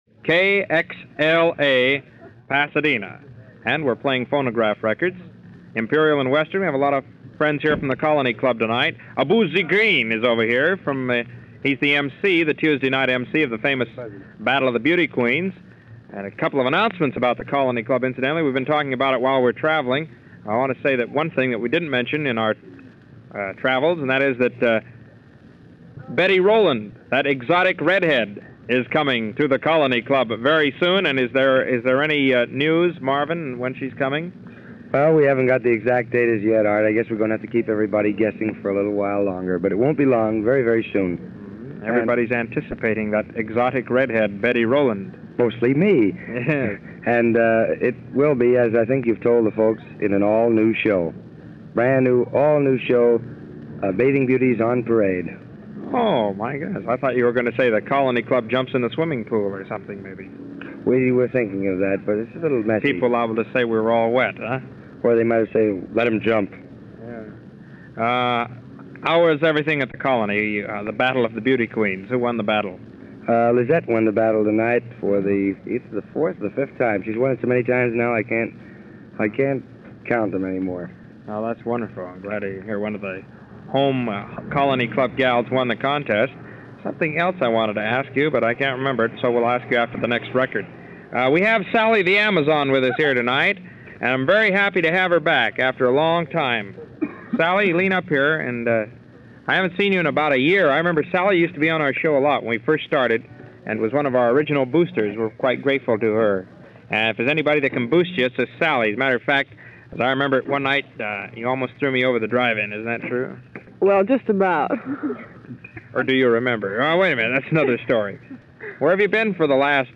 Art Laboe - fixture of early Rock n' Roll in L.A. before Rock n' Roll - hosting a DJ show in 1951 outside the Colony Club in L.A.
This show, a portion from June of 1951 when he was doing a stint at Pasadena radio station KXLA (the forerunner to KRLA).
In trademark Art Laboe fashion, he interviews people around the club while running the occasional hit record of the day.